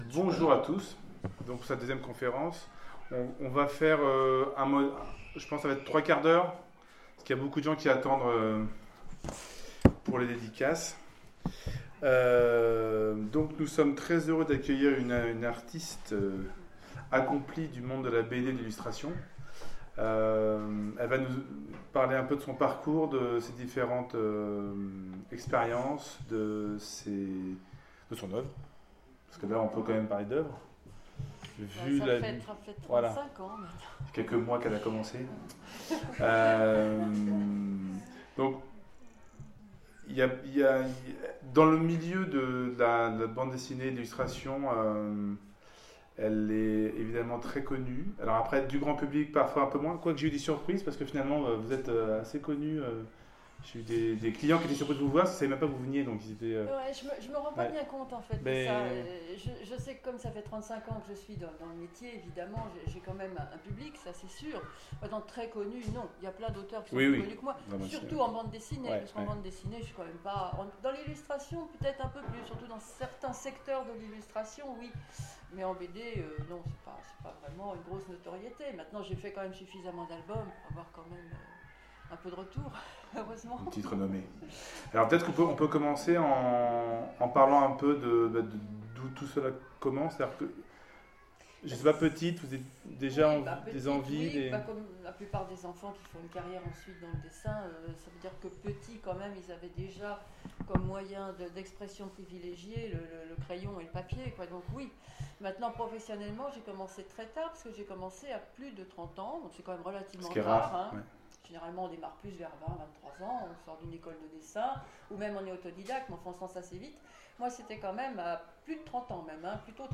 Conférence
Rencontre avec un auteur